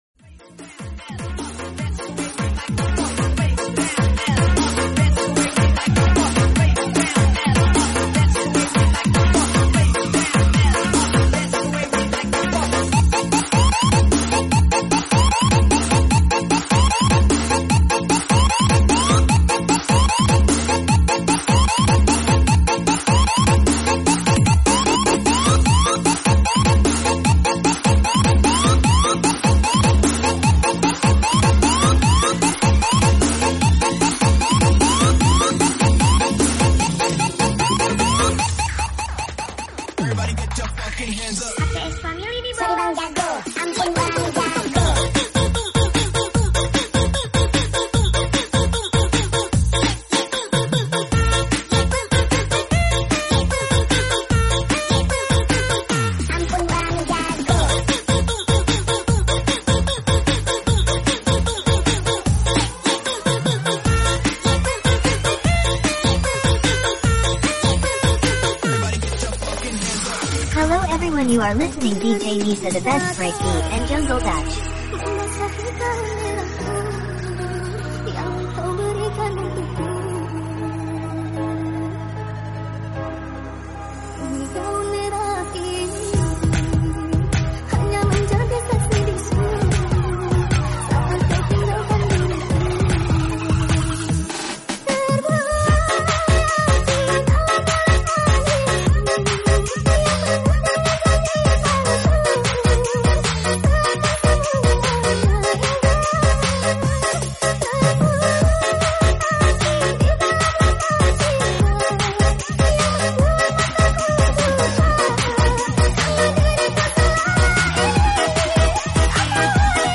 FULL BASS